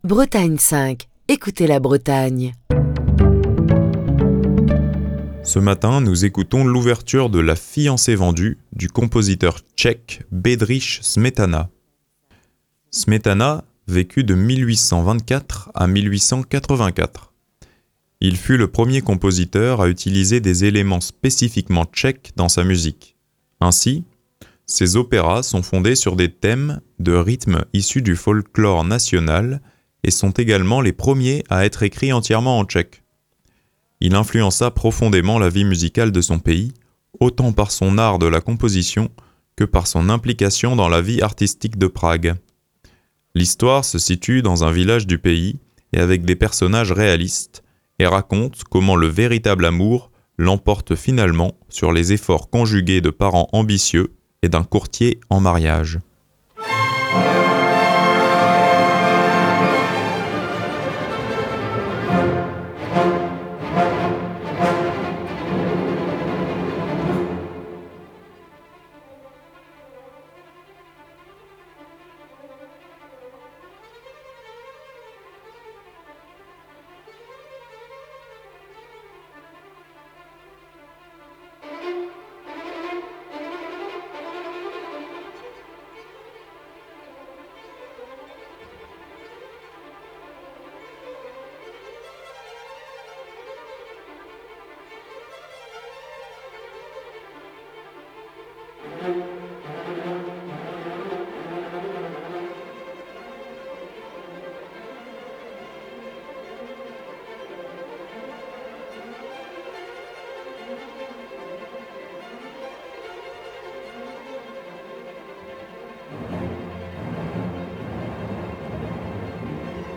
Le déploiement d’énergie pure que dégage cette ouverture de La Fiancé Vendue de Smetana, joué par l’Orchestre symphonique du Luxembourg, dirigé par Leopold Hager.